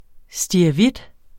Udtale [ sdiɐ̯ʌˈvid ]